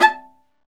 Index of /90_sSampleCDs/Roland - String Master Series/STR_Viola Solo/STR_Vla2 % marc